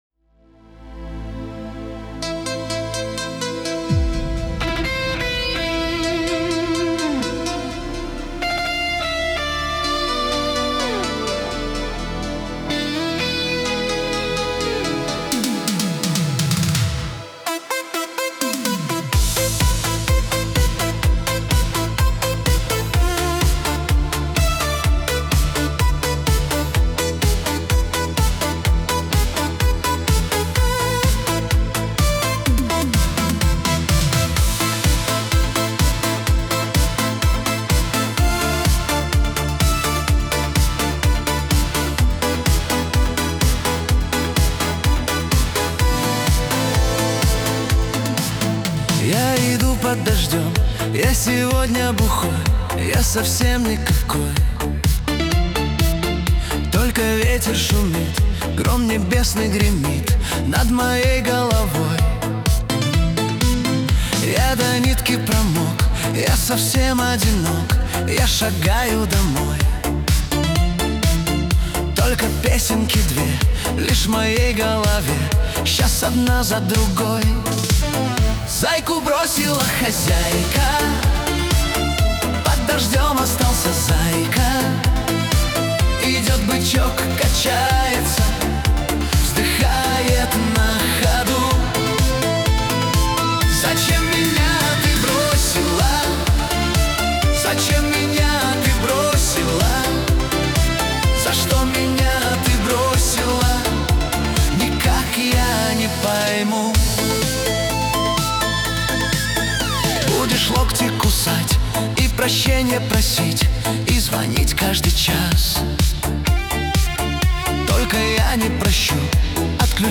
Танцевальный Шансон